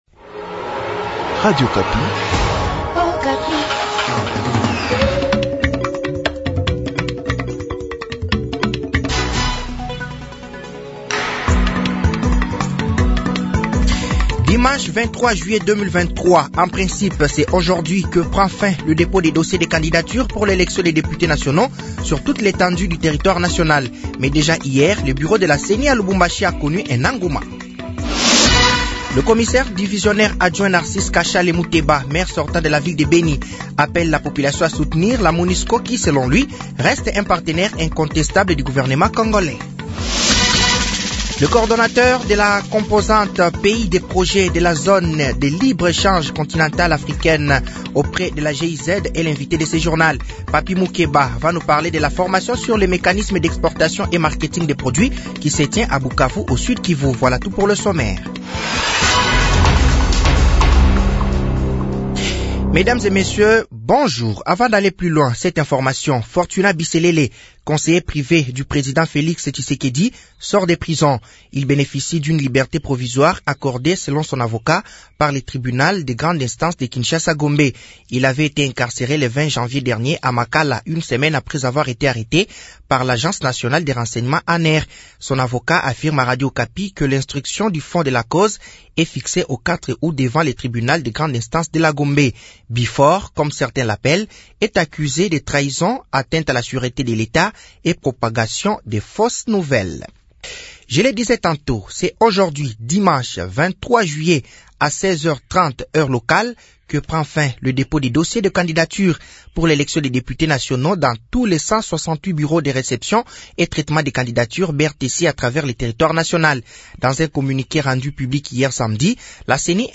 Journal français de 7h de ce dimanche 23 juillet 2023